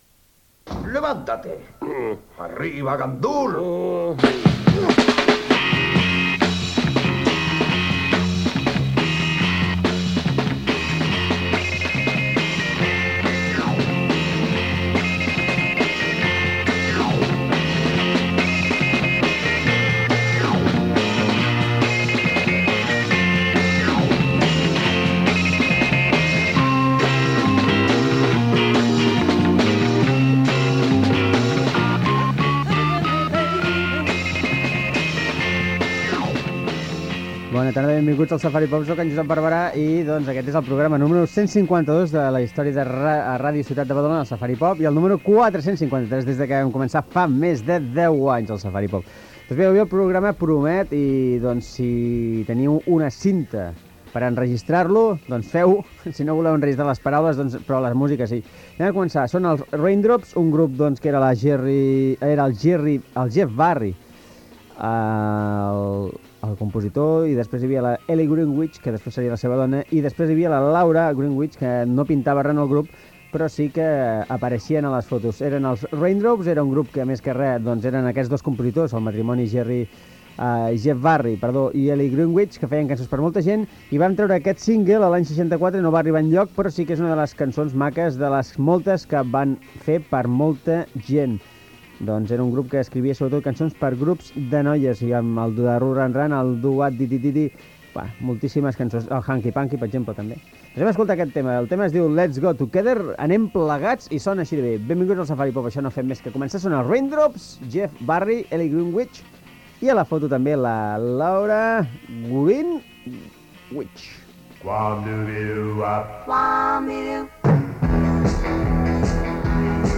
Sintonia, presentació del programa i tema musical
Musical